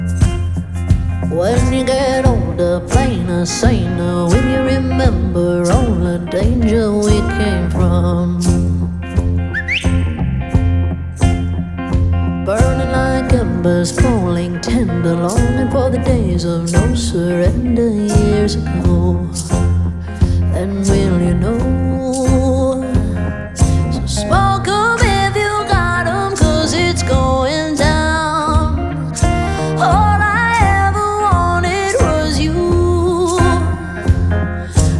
Жанр: Альтернатива
# Alternative